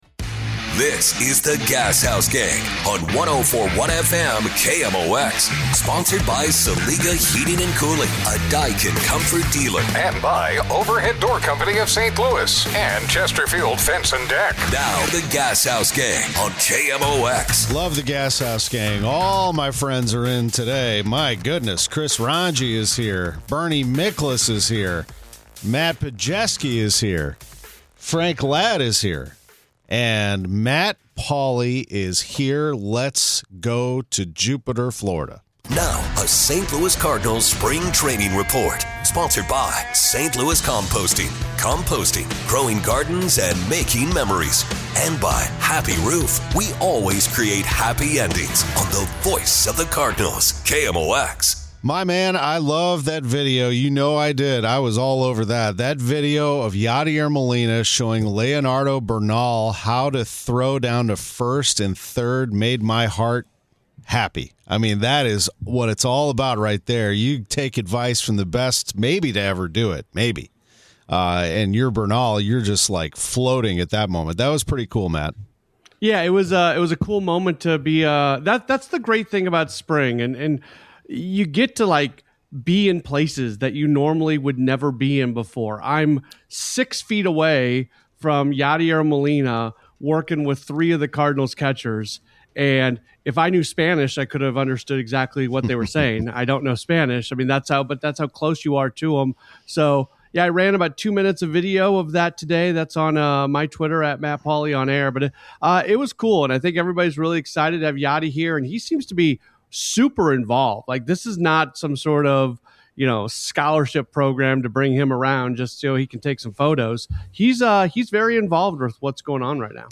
live from Cardinals spring training in Jupiter, FL.